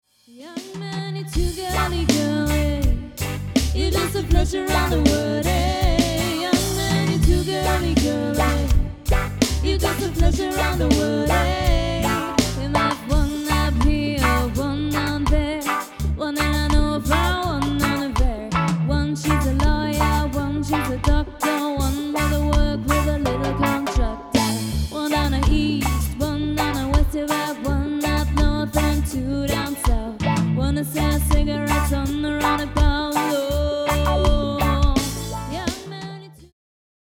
Demosounds
Cover
Gesang
Gitarre
Bass
Schlagzeug